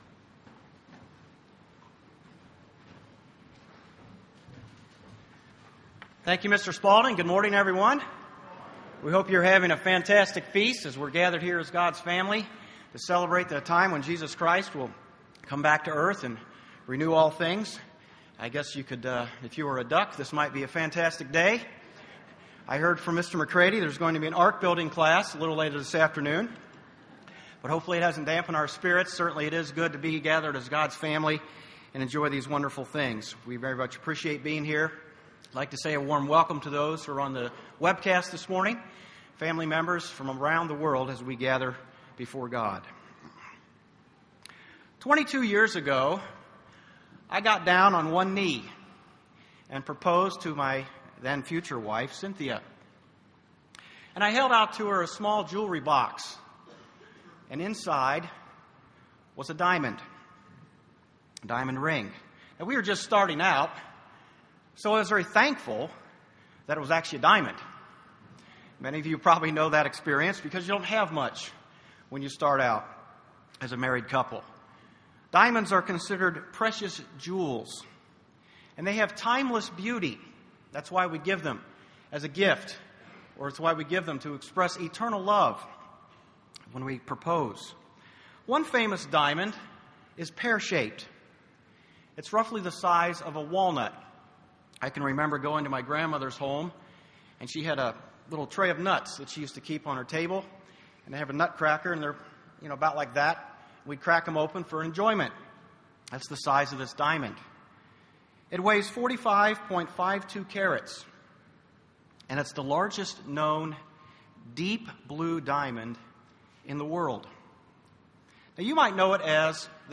This sermon was given at the Sevierville, Tennessee 2015 Feast site.